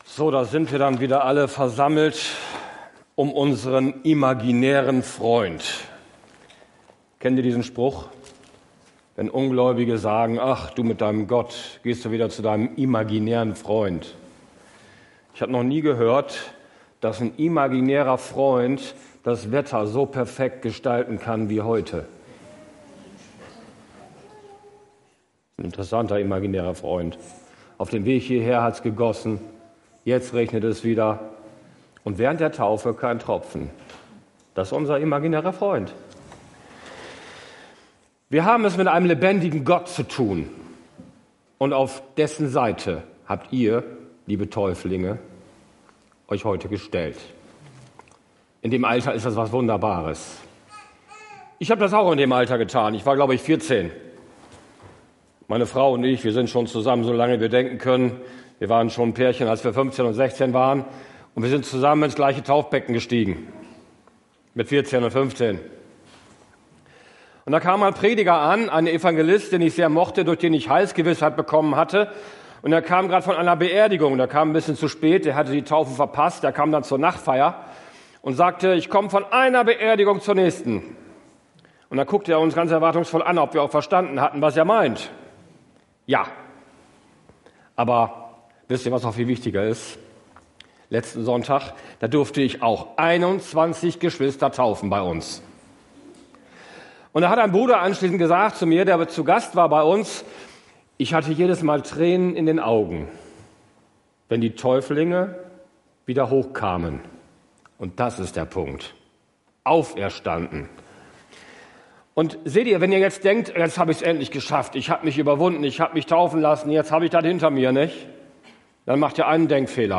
Prediger